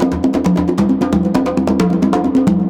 CONGABEAT9-L.wav